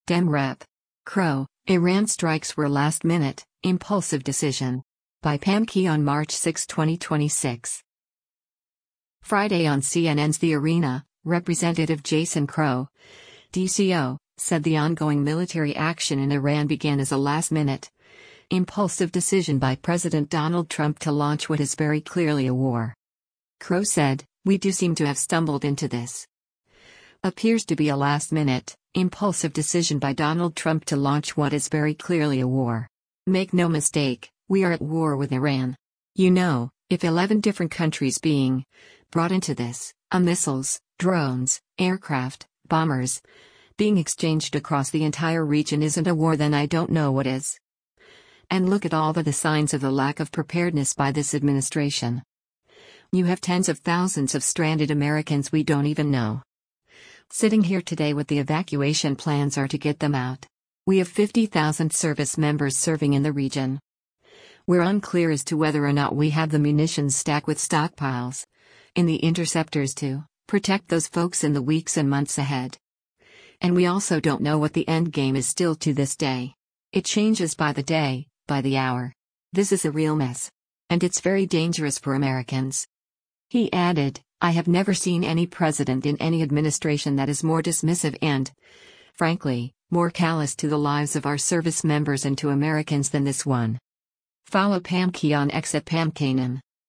Friday on CNN’s “The Arena,” Rep. Jason Crow (D-CO) said the ongoing military action in Iran began as a “last minute, impulsive decision” by President Donald Trump to launch what is very clearly a war.